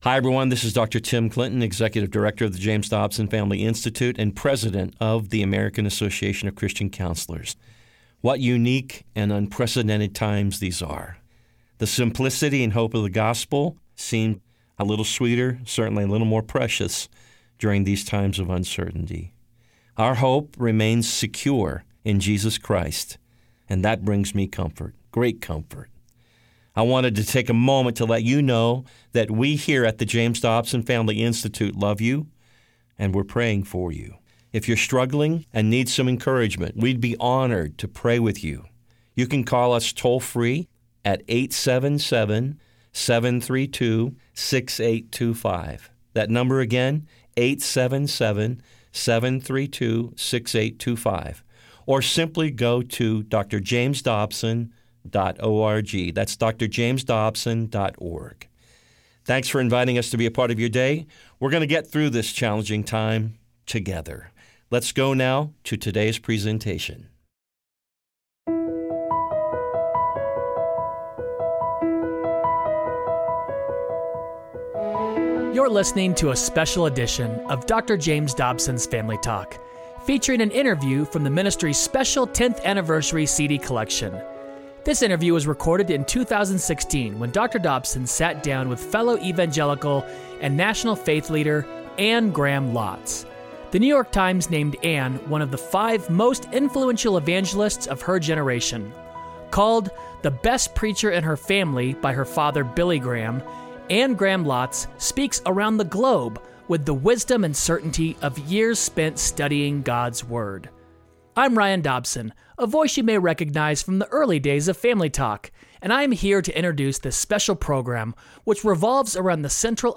On this 10th anniversary edition of Family Talk, youll hear Dr. Dobsons timeless conversation about the power of prayer with author and evangelist Anne Graham Lotz. She unpacks the ways we can cry out to God with that same desperation to heal our morally failing country.